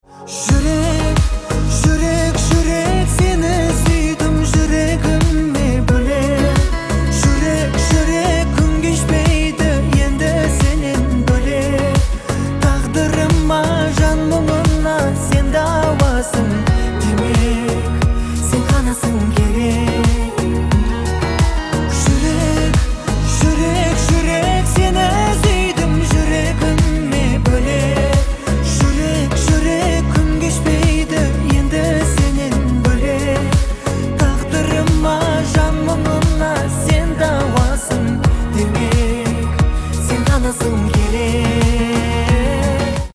• Качество: 128, Stereo
поп
мужской вокал
Приятный казахский поп